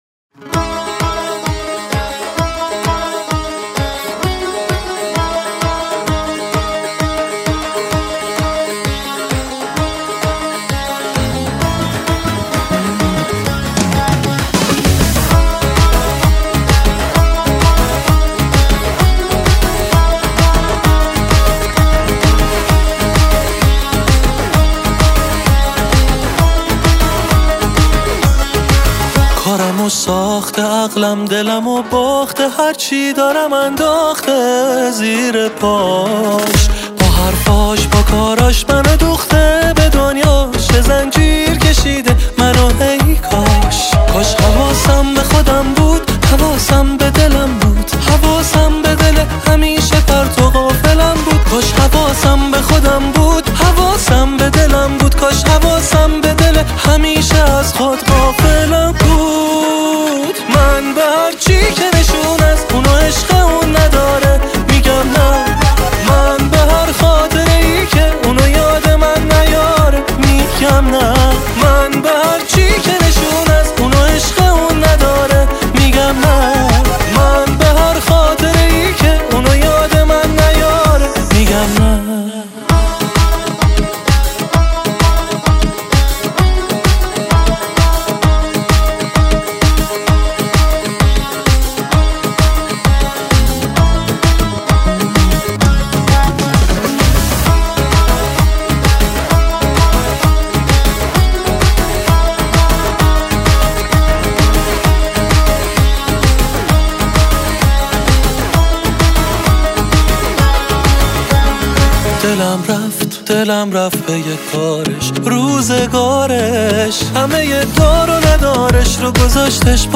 پرکاشن
تار
گیتار